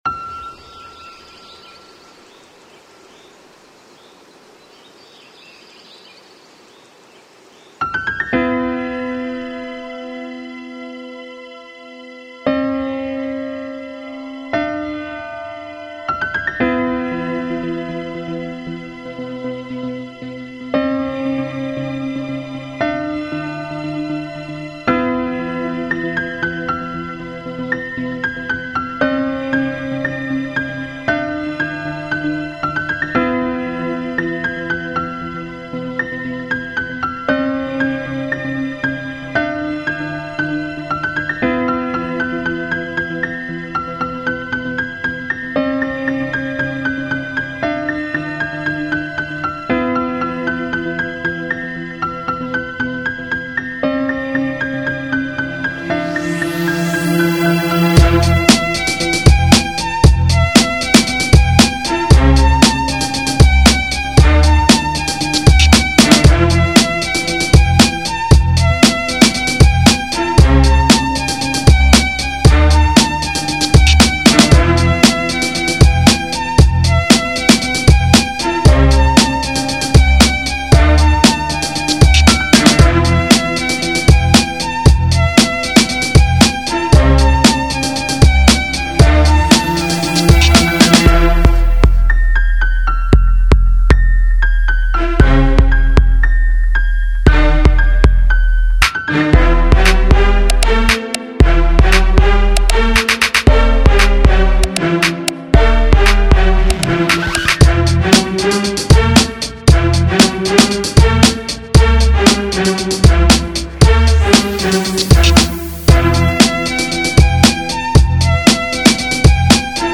использует вокальные гармонии и электронные элементы